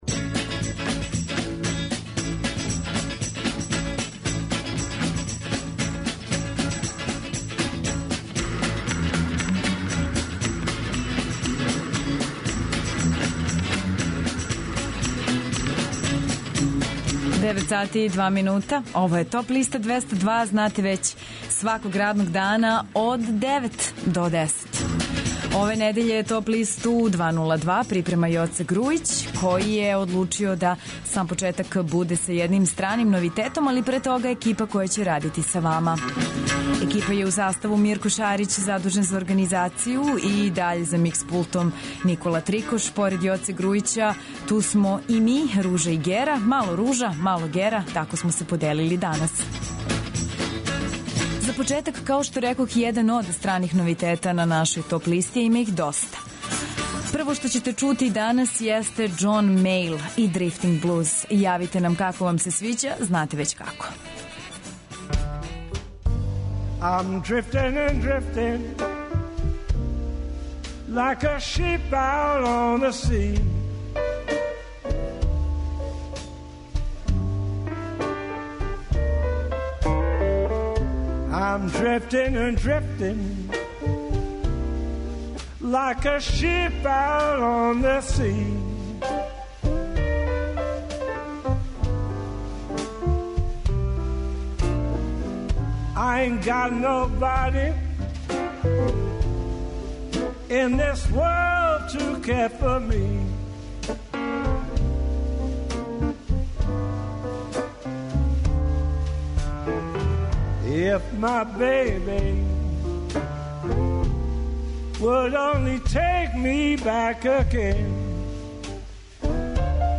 Најавићемо актуелне концерте у овом месецу, подсетићемо се шта се битно десило у историји рок музике у периоду од 07. до 11. септембра. Ту су и неизбежне подлисте лектире, обрада, домаћег и страног рока, филмске и инструменталне музике, попа, етно музике, блуза и џеза, као и класичне музике.